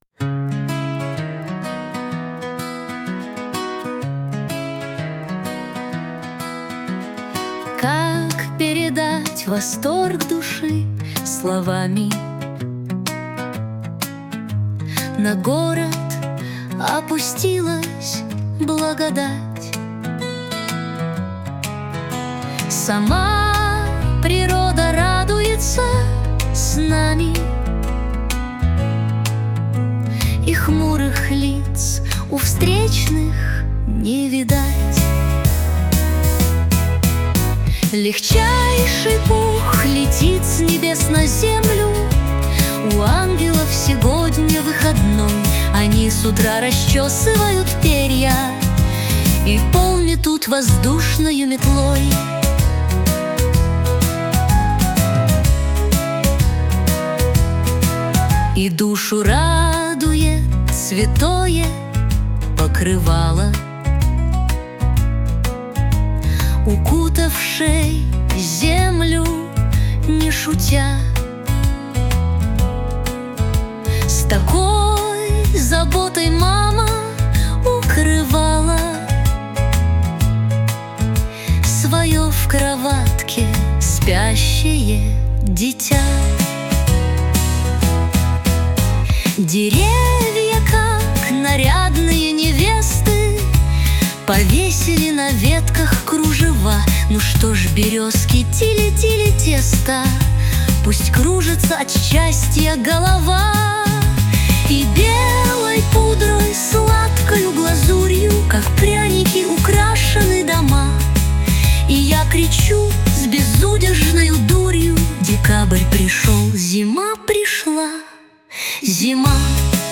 • Аранжировка: Ai
• Жанр: Поп